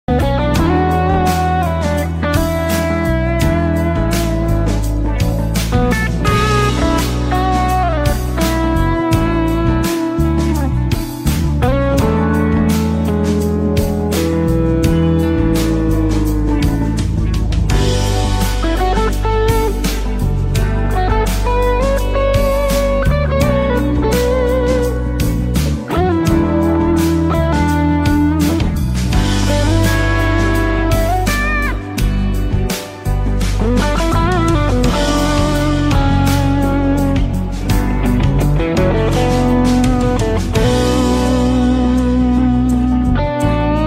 Guitar Improvisation Sound Effects Free Download